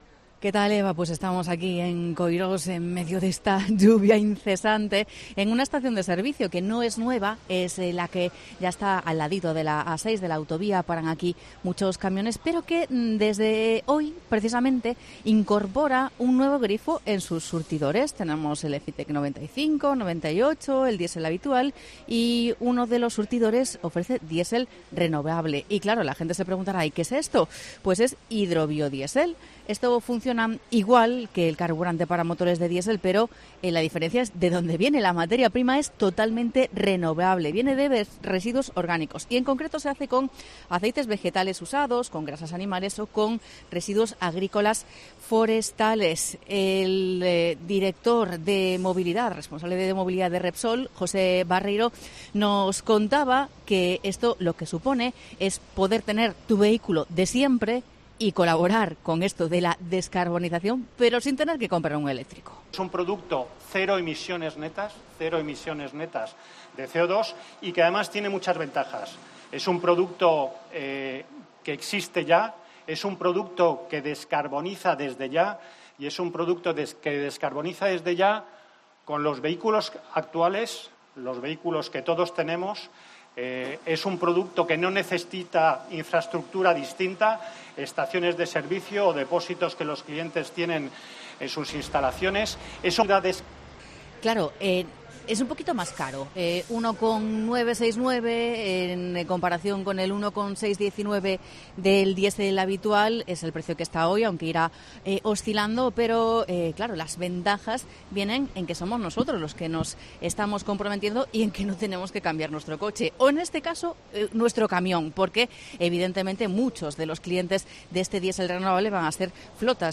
Crónica desde Coirós de la primera estación de servicio con combustible 100% renovable en Galicia